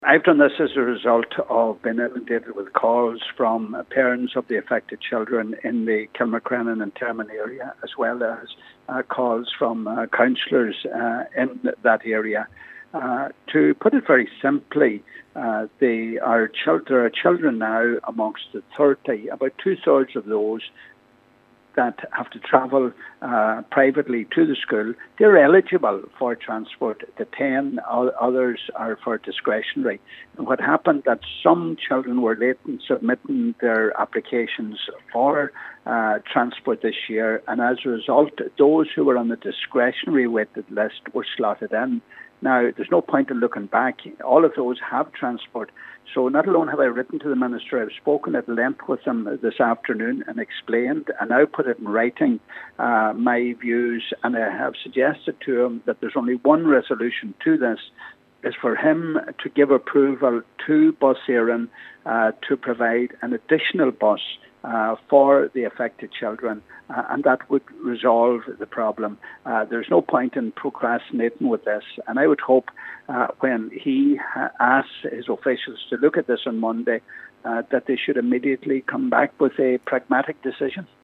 Donegal Deputy Pat the Cope Gallagher has called on Minister John Halligan to approve an additional school bus in a bid to resolve the issue: